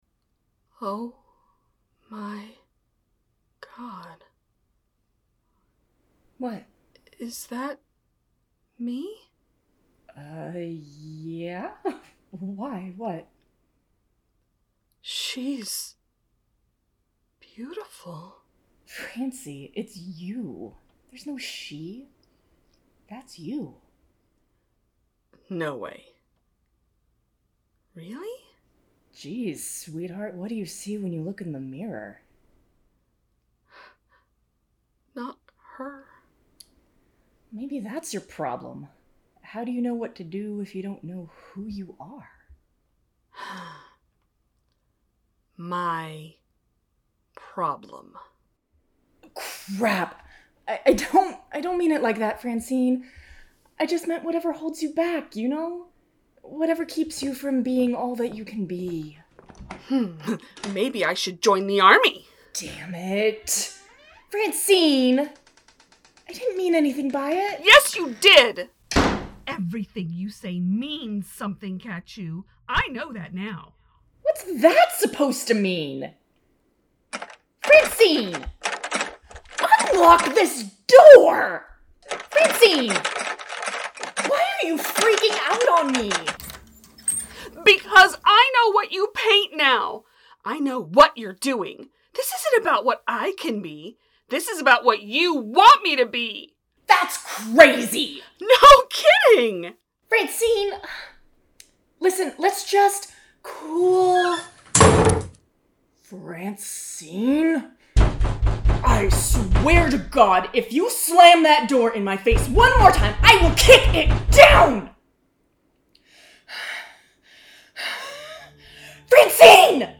Strangers In Paradise – The Audio Drama – Book 7 – Episode 7 – Two True Freaks
The Ocadecagonagon Theater Group